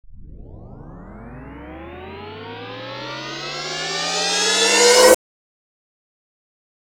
Crash Transition Sound Effect Free Download
Crash Transition